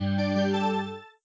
Login.wav